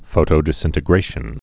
(fōtō-dĭs-ĭntĭ-grāshən)